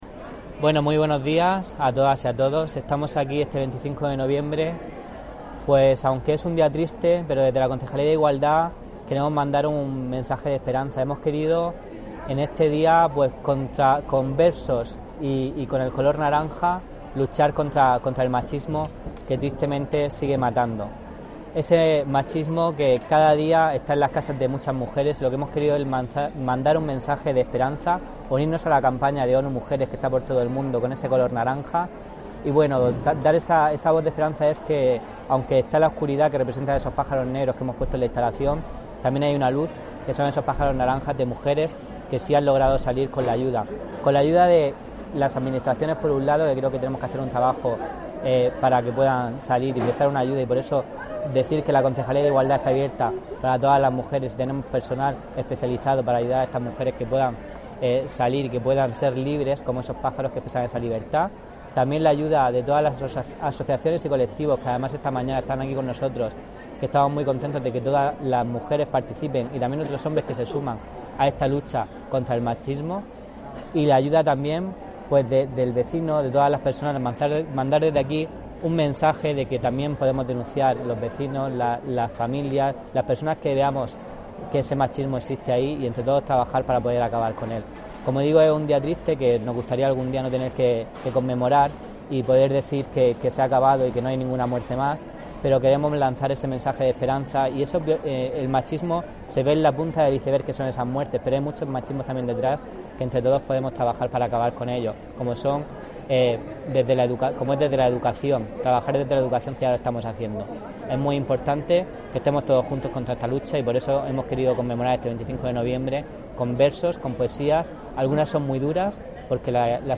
Dentro de los actos del Día Internacional de la eliminación de la violencia contra la mujer, la plaza de San Sebastián acogía hoy viernes la lectura poética Ámame Libre, que ha contado con la asistencia del concejal de Igualdad